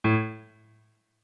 MIDI-Synthesizer/Project/Piano/25.ogg at 51c16a17ac42a0203ee77c8c68e83996ce3f6132